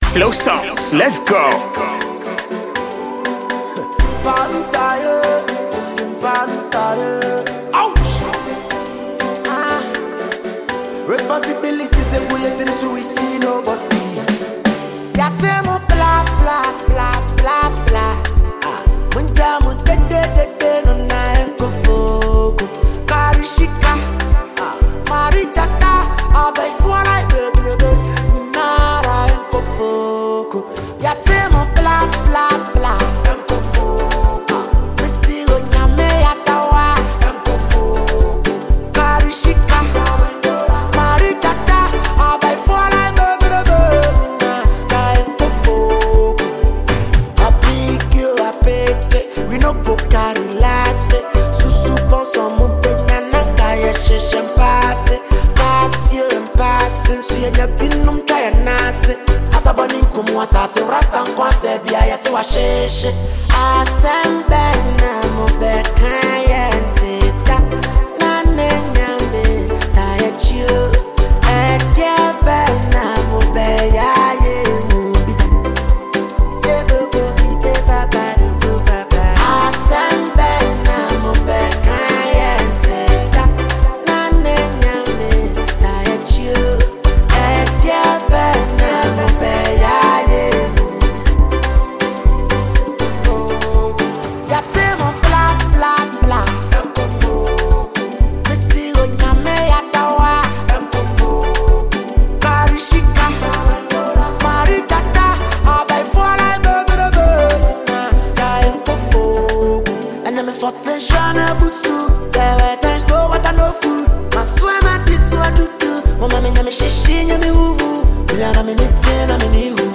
Ghanaian Rapper -songwriter
This soulful track
With its catchy melody and heartfelt lyrics